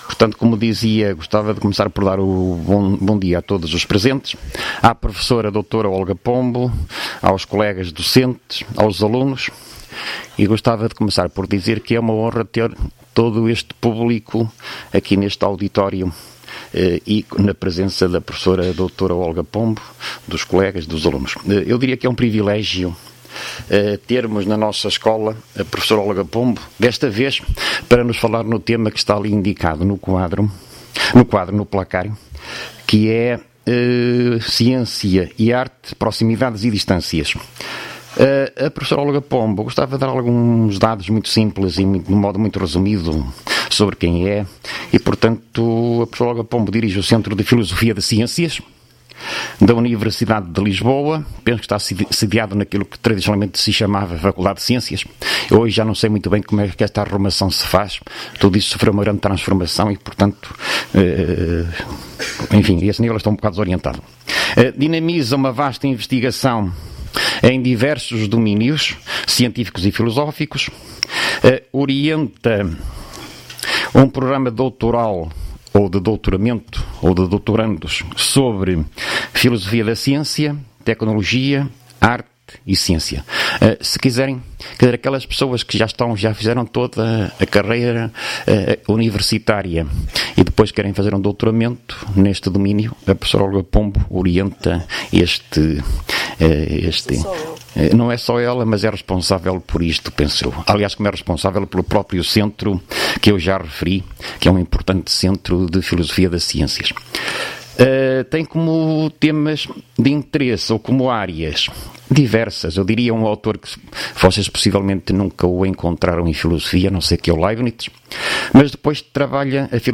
Conferência